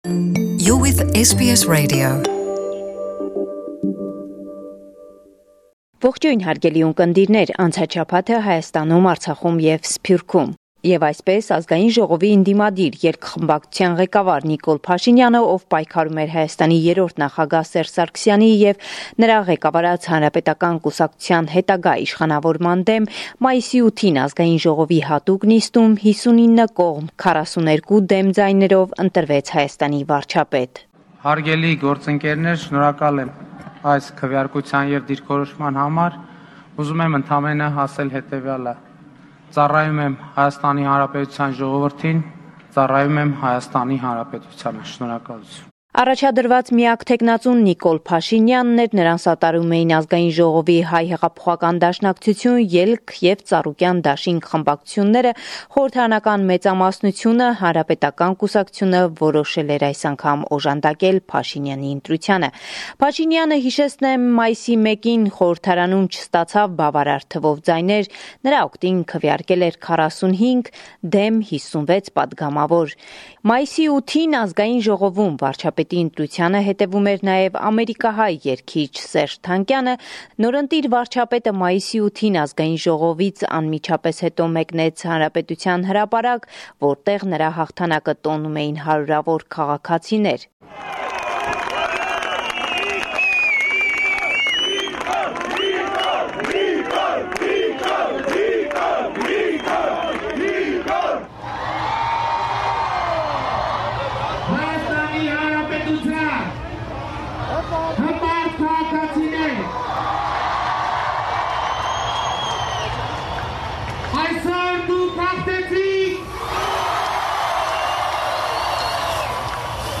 Վերջին Լուրերը – 15 Մայիս 2018